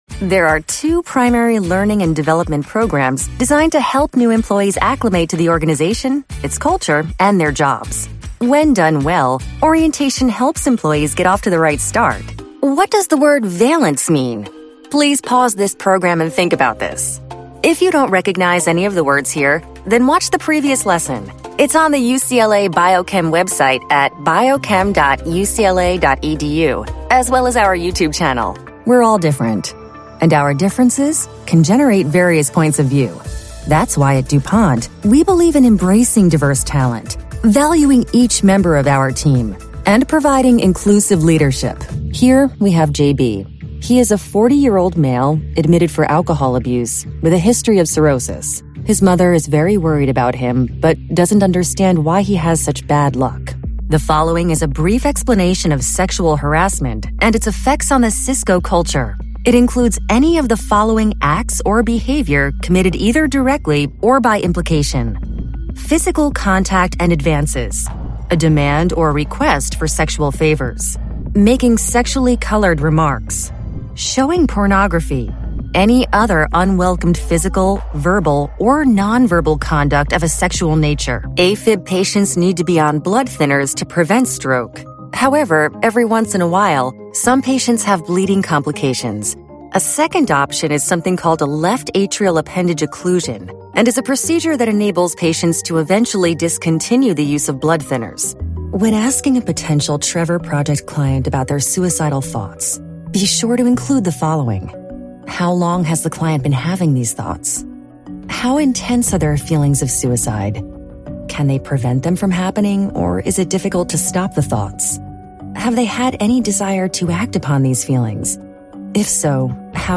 Comedic and savvy a la Tina Fey, Jenny Slate, Sarah Silverman.
eLearning Demo
Young Adult
Middle Aged